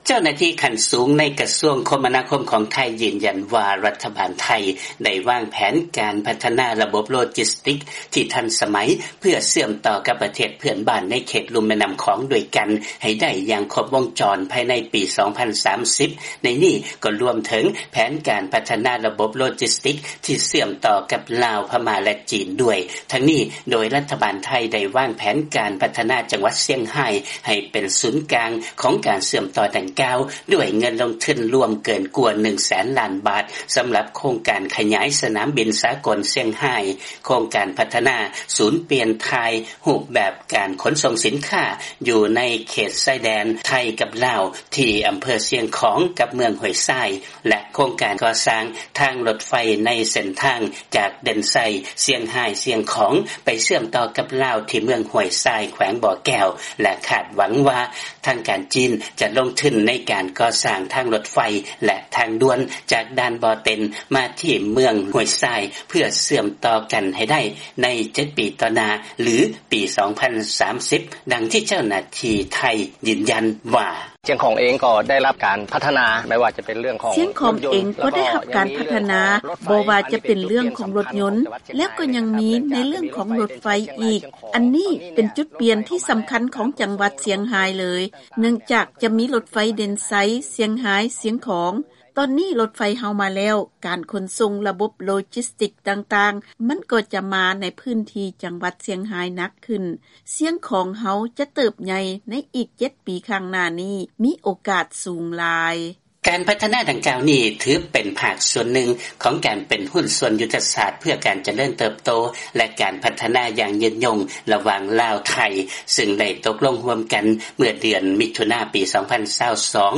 ເຊີນຟັງລາຍງານກ່ຽວກັບໄທຈະສ້າງຈັງຫວັດຊຽງຮາຍໃຫ້ເປັນສູນກາງ ໂລຈິສຕິກສຳລັບເຂດລຸ່ມແມ່ນ້ຳຂອງຕອນເທິງ